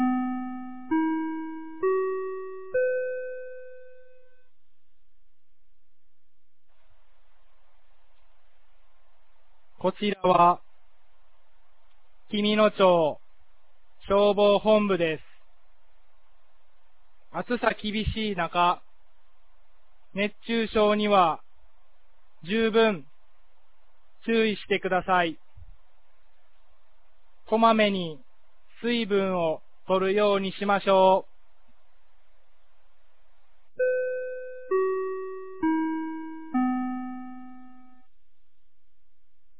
2025年07月19日 16時00分に、紀美野町より全地区へ放送がありました。